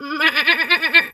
pgs/Assets/Audio/Animal_Impersonations/sheep_2_baa_02.wav at master
sheep_2_baa_02.wav